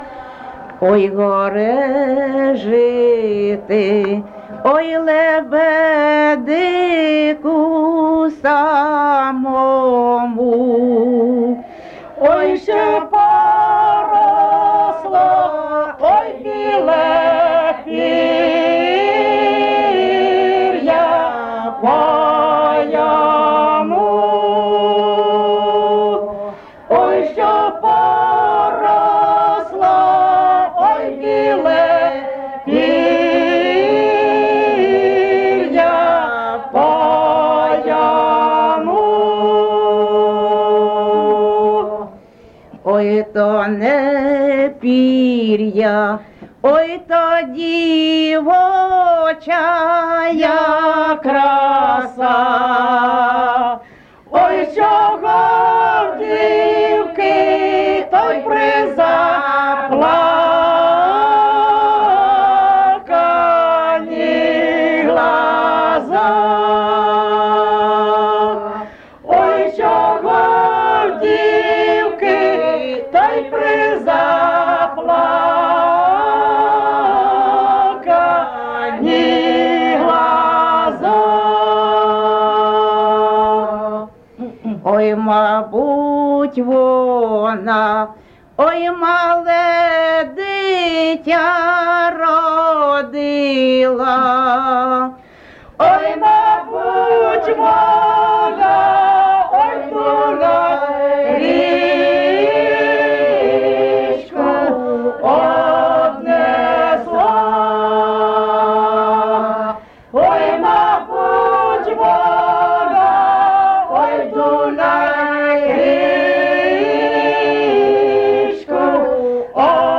Місце записус. Лиман, Зміївський (Чугуївський) район, Харківська обл., Україна, Слобожанщина